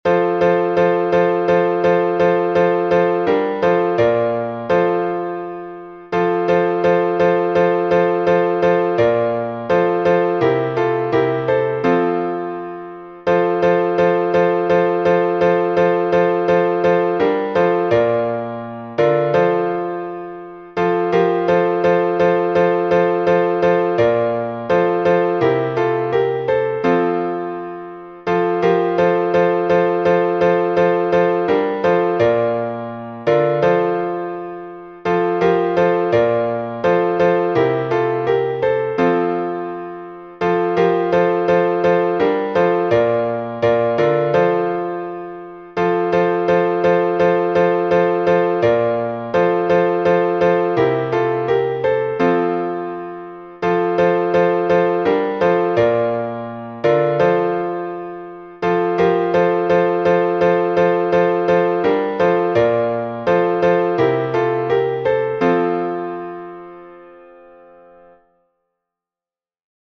Болгарский распев